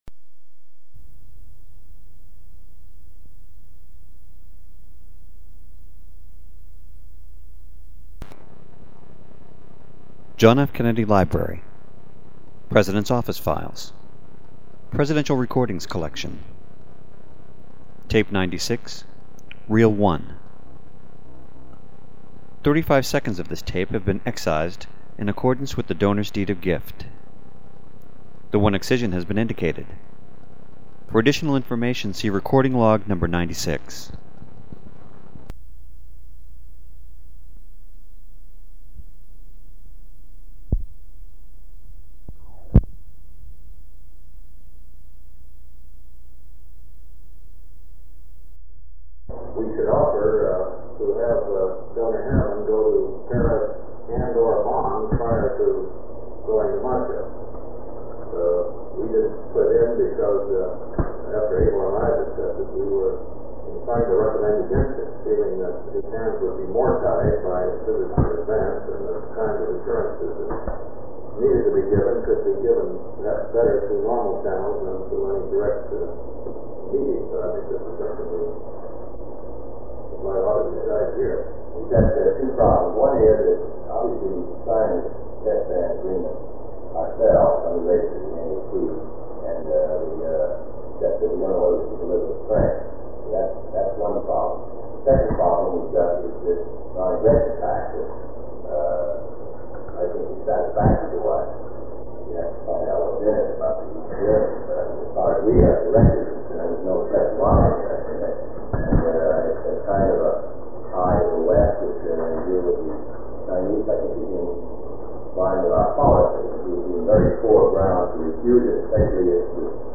Sound recording of a meeting held on July 3, 1963, between President John F. Kennedy, Secretary of State Dean Rusk, Under Secretary of State George Ball, Under Secretary of State W. Averell Harriman, Assistant Secretary of State William R. Tyler, Military Representative of the President Maxwell Taylor, Director of the U.S. Arms Control and Disarmament Agency (ACDA) William Foster, Special Assistant to the President for Science and Technology Dr. Jerome B. Wiesner, Deputy Special Assistant to the
This sound recording has been excerpted from Tape 96, which contains additional sound recording(s) following this one.